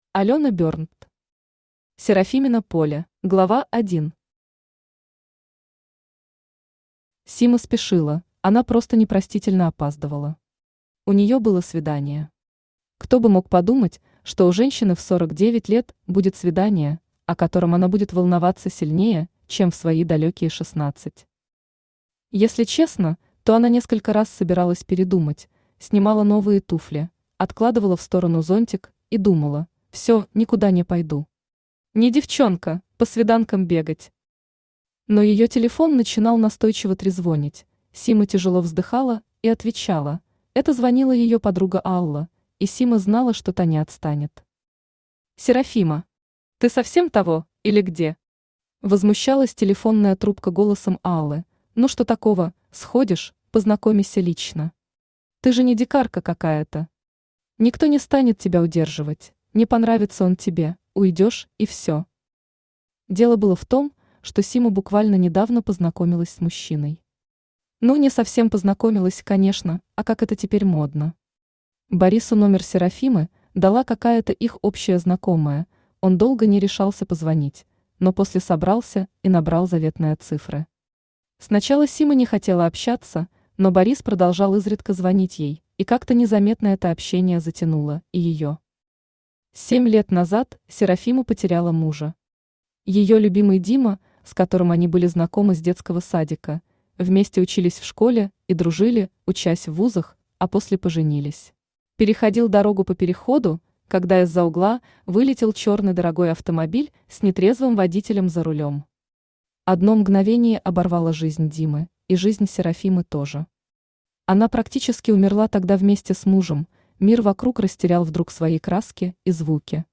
Аудиокнига Серафимино поле | Библиотека аудиокниг
Aудиокнига Серафимино поле Автор Алёна Берндт Читает аудиокнигу Авточтец ЛитРес.